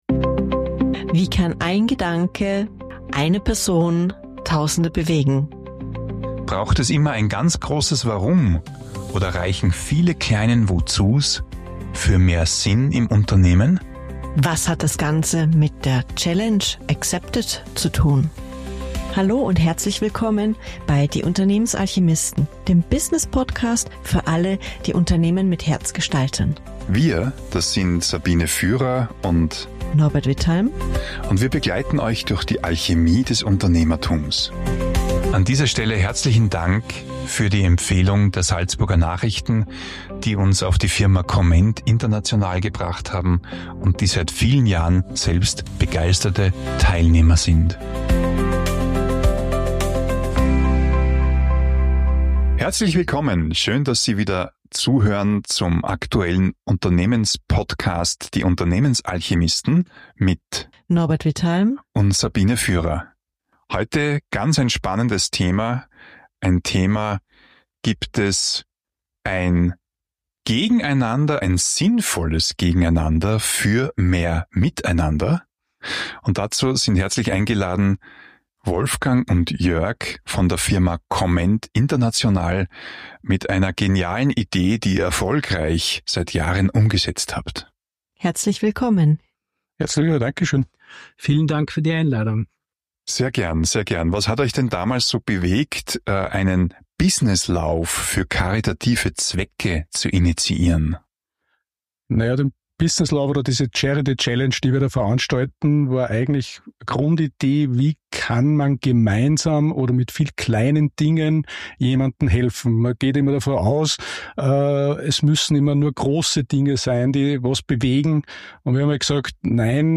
Ein inspirierendes Gespräch über gelebtes Miteinander im Arbeitsalltag.